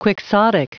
Prononciation du mot : quixotic